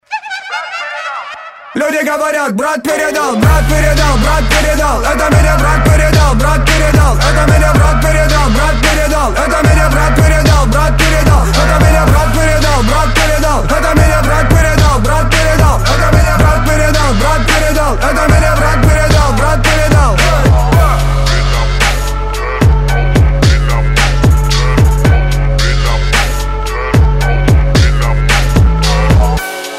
Trap рингтоны